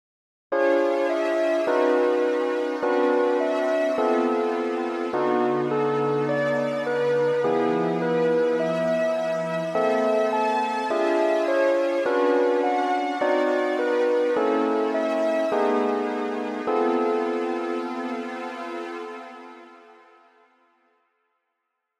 響きは「すっきり・あいまい」という感じです。
という訳で、以下の実施例は上三声に接触する位置(付加音に近い形）を織り交ぜています。